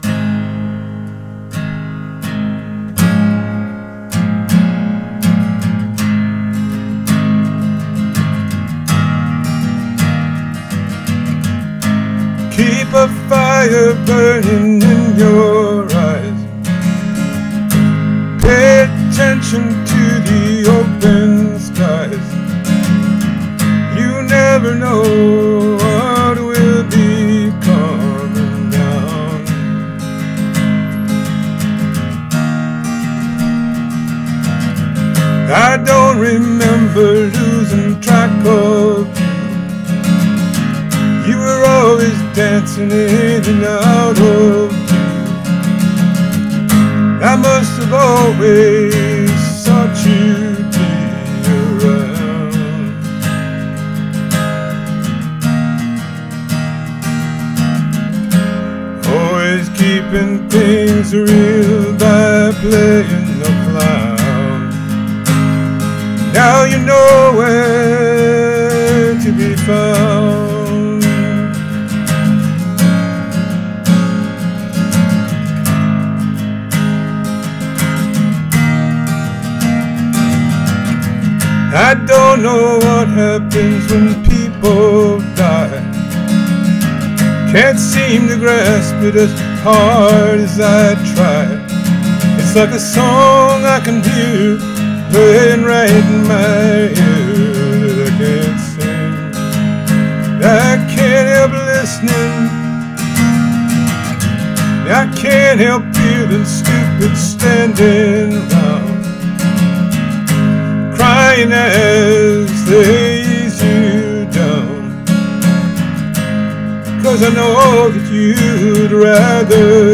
Here’s my cover.